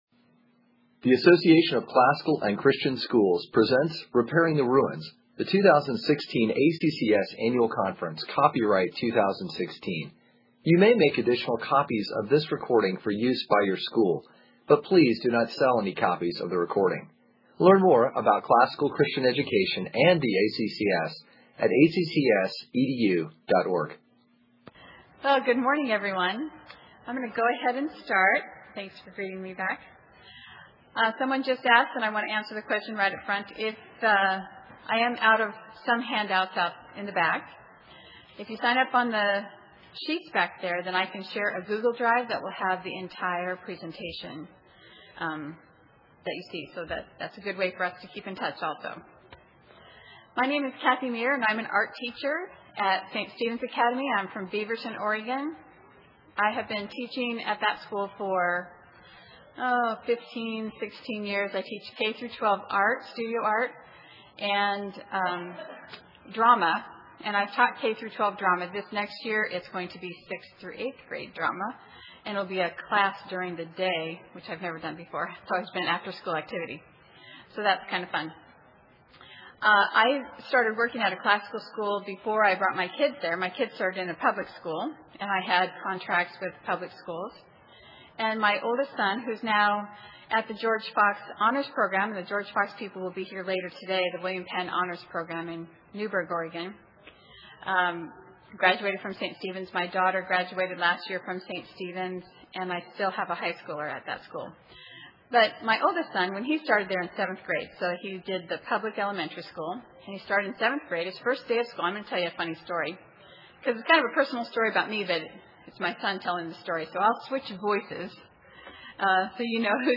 2016 Workshop Talk | 1:05:43 | All Grade Levels, Art & Music